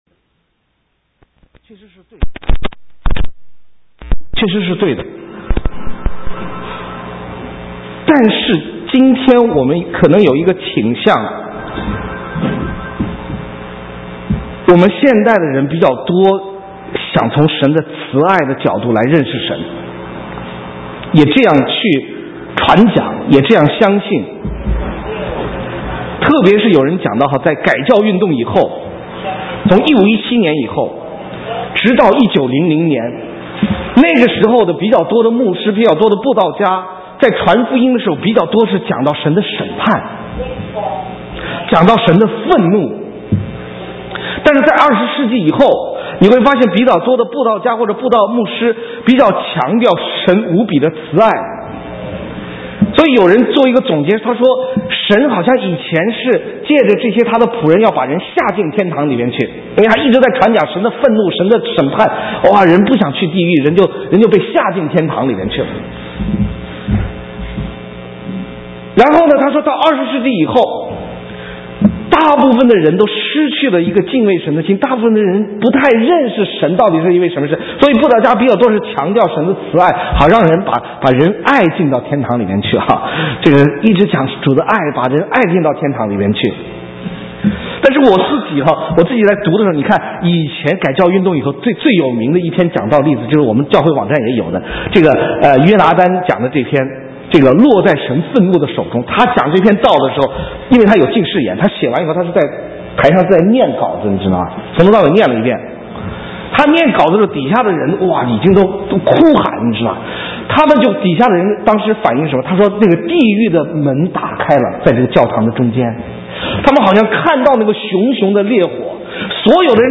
神州宣教--讲道录音 浏览：神的愤怒 (2012-01-01)